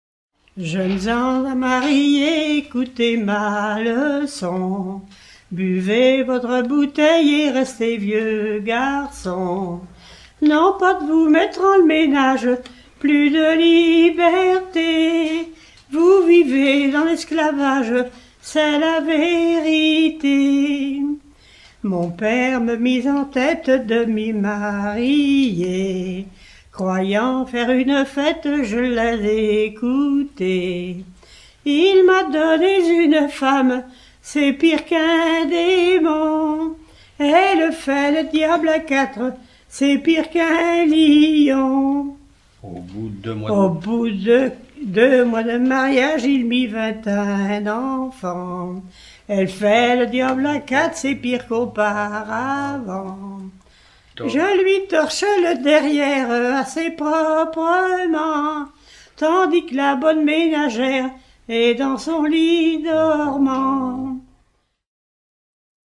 Merlatière (La)
Genre strophique